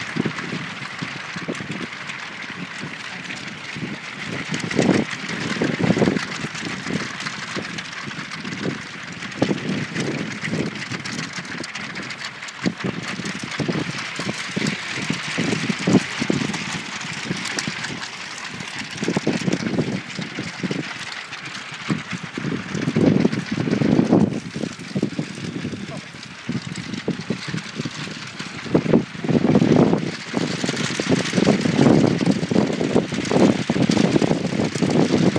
Flags. Wet jubilee flags